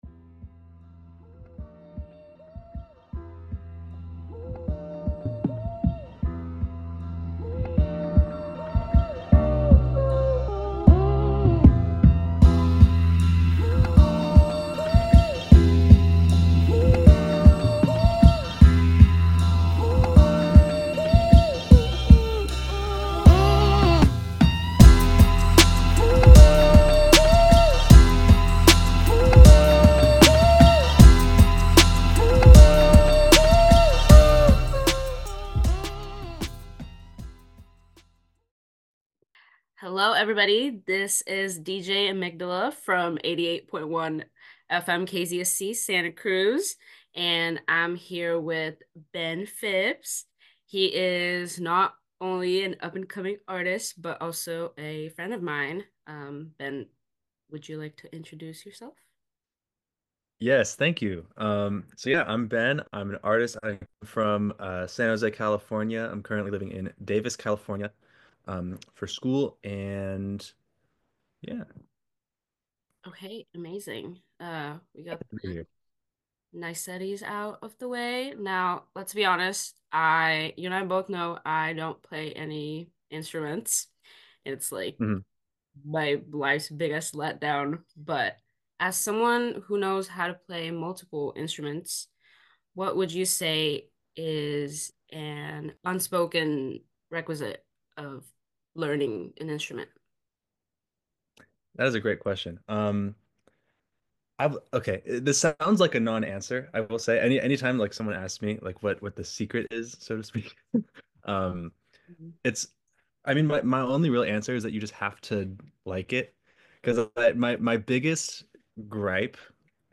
Interviews | KZSC Santa Cruz